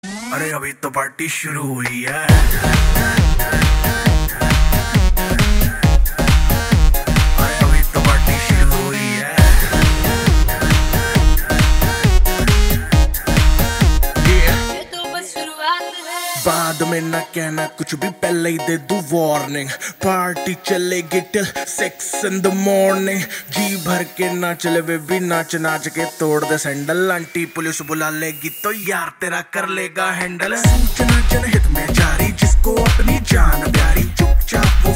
a superhit party song with energetic beats and lively vibes.